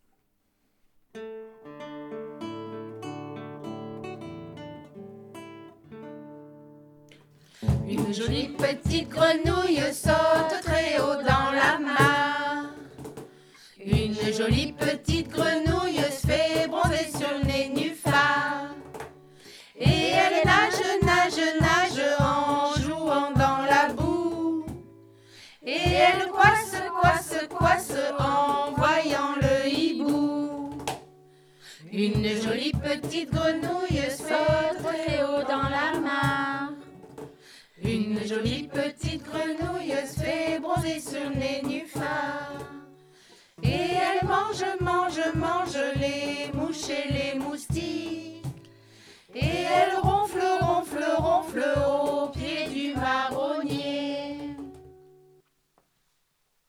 Des comptines pour les petits :
7 assistantes maternelles du RPE des Côteaux et du Plateau ont  collaboré pour mettre en musique cinq comptines qui ont été mises en musique avec des enseignants de l'école Intercommunale de musique et danse de Arche Agglo, et que vous pouvez retrouver ici.